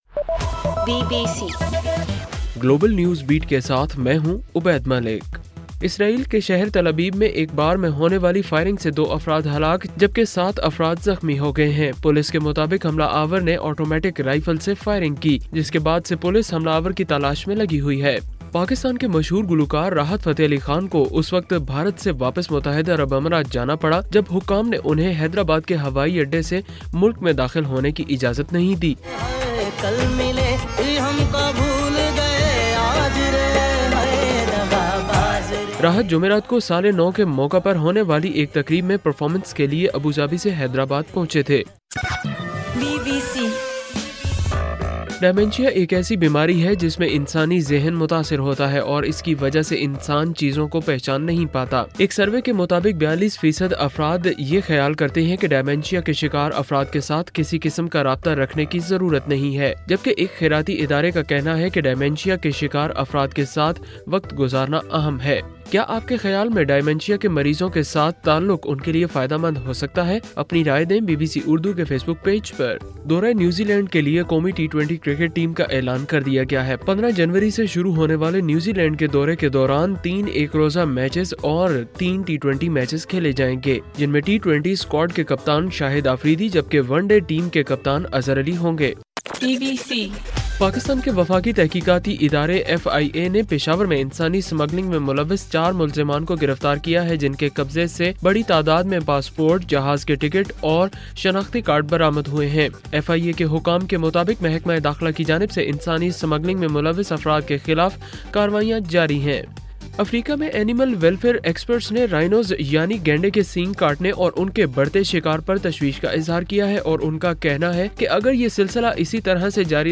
جنوری 02: صبح 1 بجے کا گلوبل نیوز بیٹ بُلیٹن